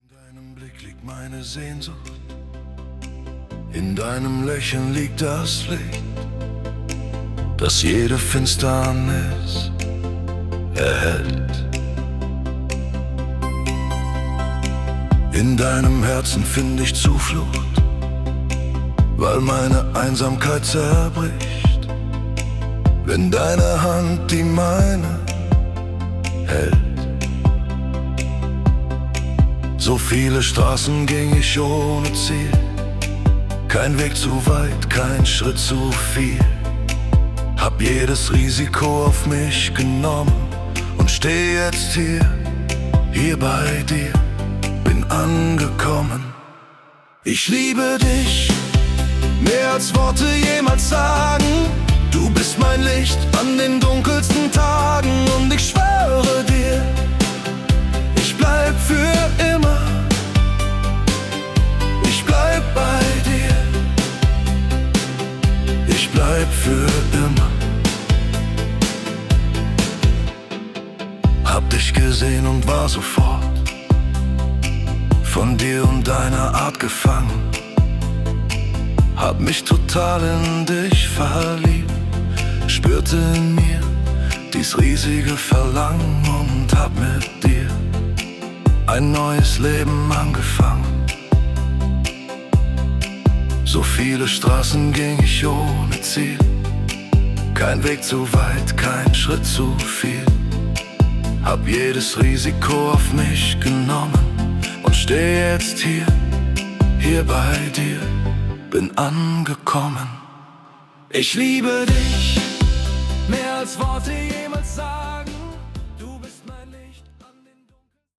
(Schlager)